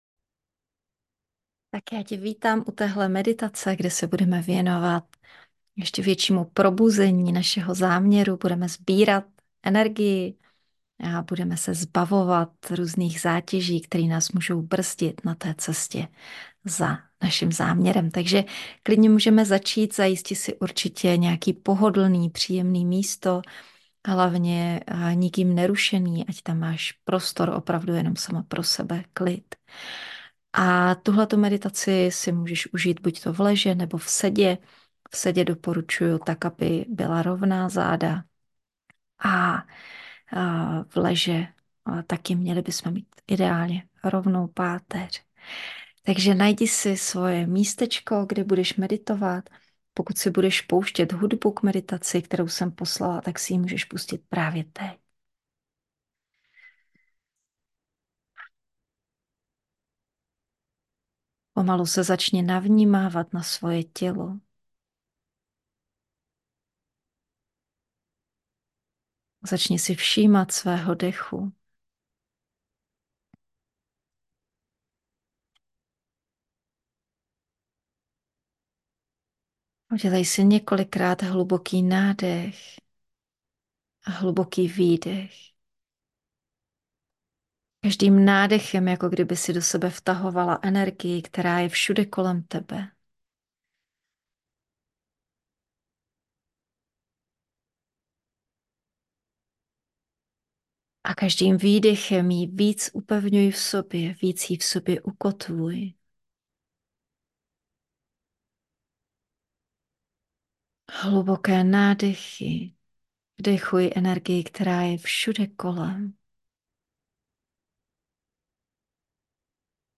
meditace_zamer.mp3